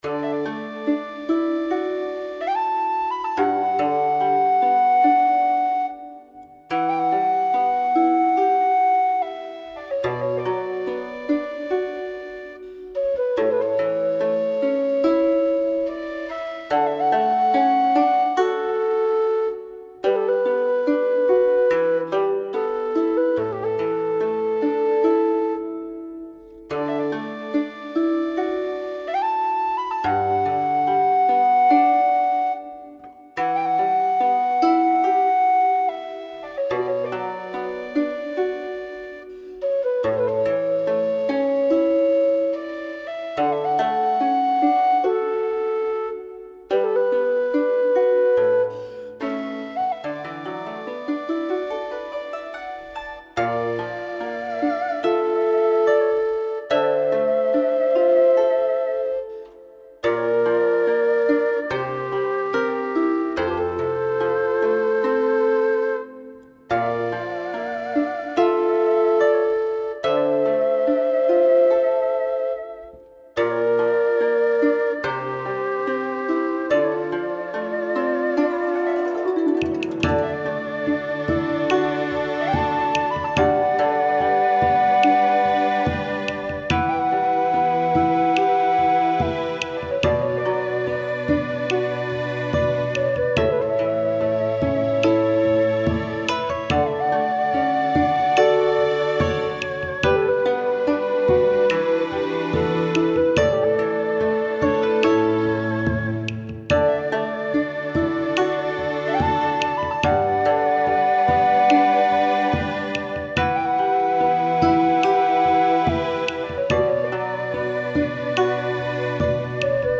ファンタジー系フリーBGM｜ゲーム・動画・TRPGなどに！
おしゃれな町並みとかそういう感じのやつ。